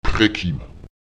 Lautsprecher prekim [ÈprEkim] töten, umbringen